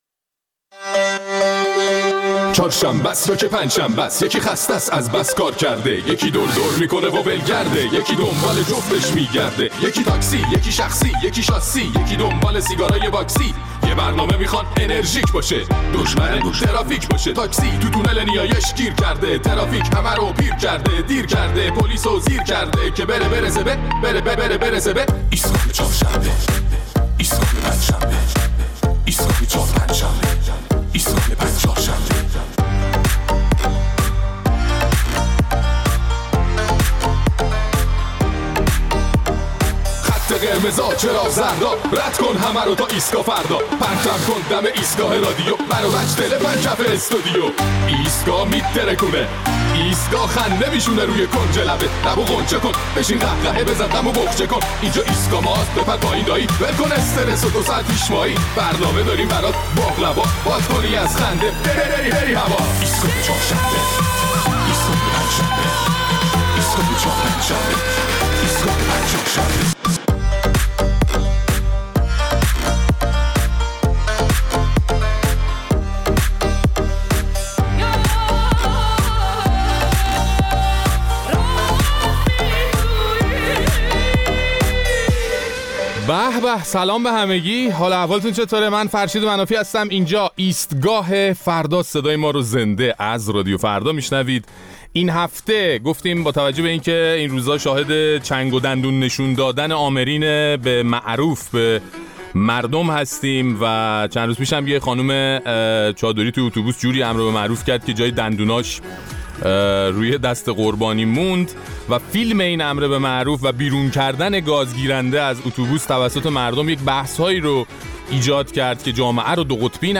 در این برنامه ادامه نظرات شنوندگان ایستگاه فردا را در مورد درگیری‌های اخیر بین نیروها و افراد وابسته به حکومت با مردم و نگرانی‌ها از قطبی شدن جامعه می‌شنویم.